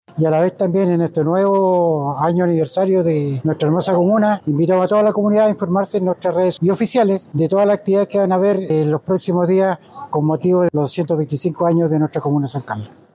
Jorge-Rodriguez-concejal-1.mp3